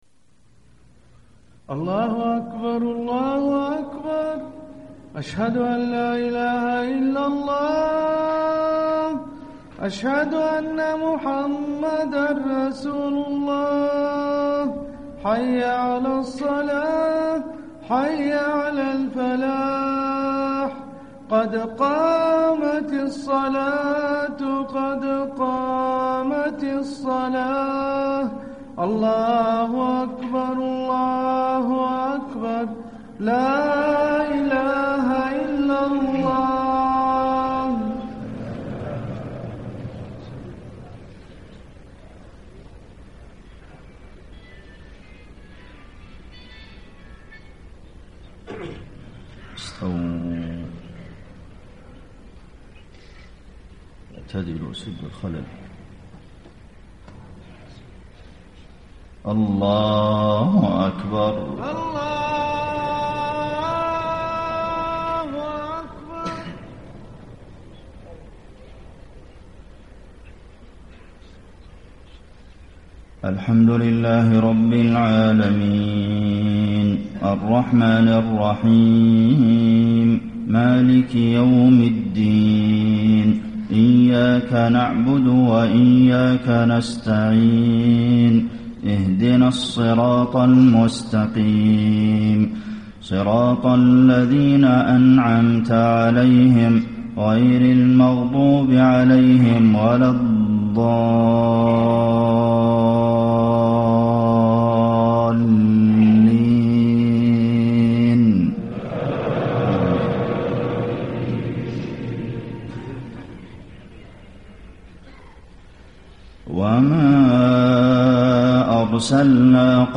فجر 22 شعبان ١٤٣٥ من سورة الفرقان > 1435 🕌 > الفروض - تلاوات الحرمين